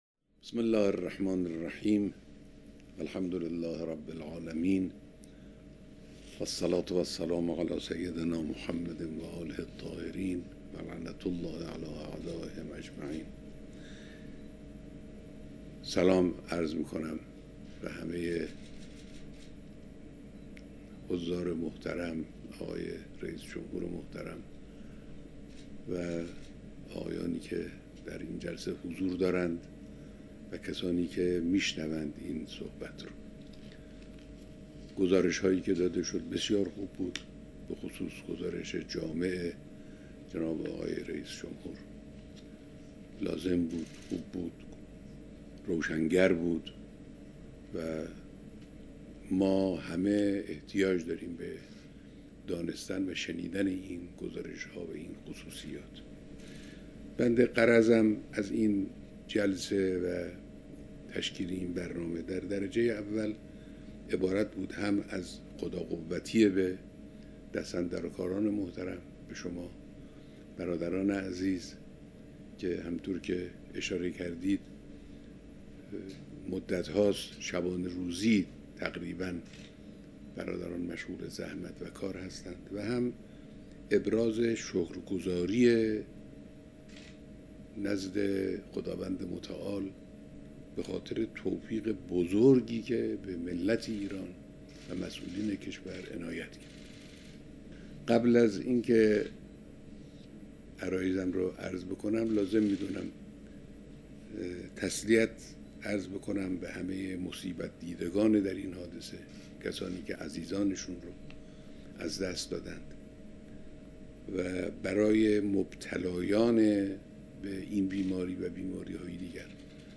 بیانات در ارتباط تصویری با ستاد ملی مبارزه با کرونا